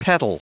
Prononciation du mot petal en anglais (fichier audio)
Prononciation du mot : petal